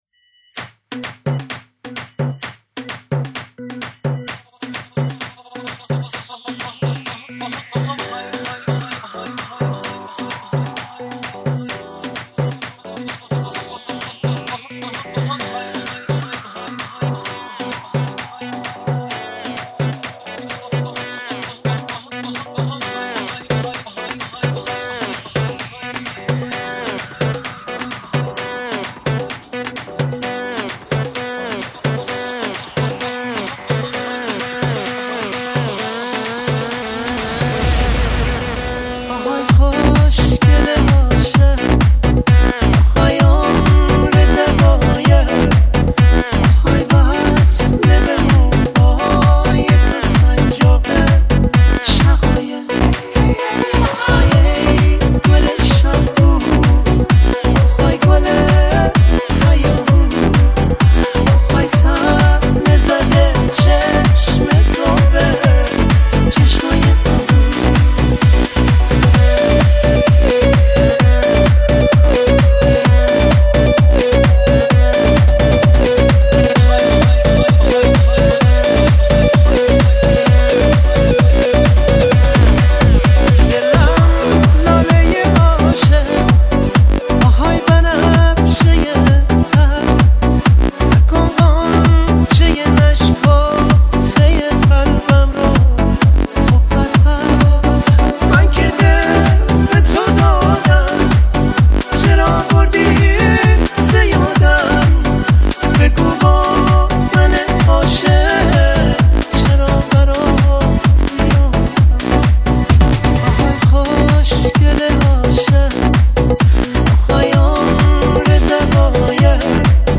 دسته بندی : دانلود آهنگ غمگین تاریخ : دوشنبه 27 می 2019